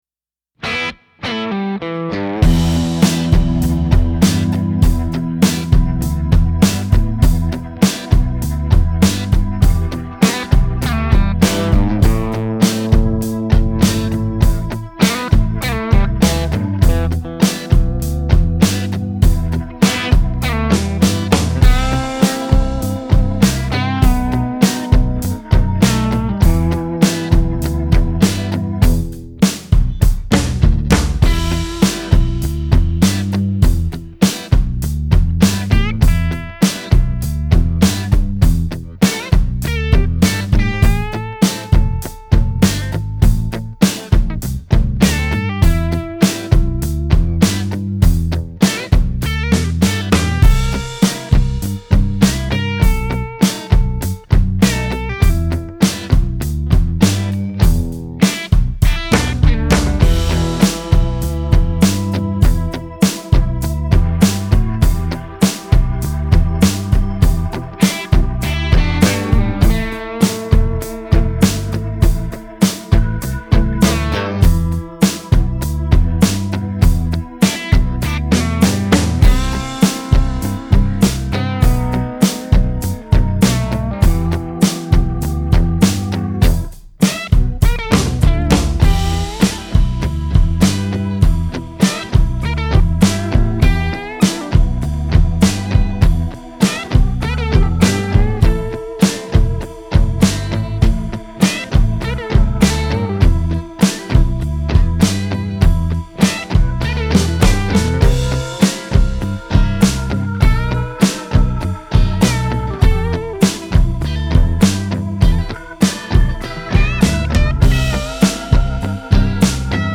Bruk følgende bakgrunnsmusikk når du øver på å improvisere:
Backingtrack i A